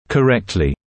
[kə’rektlɪ][кэ’рэктли]правильно, верно, корректно